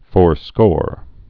(fôrskôr)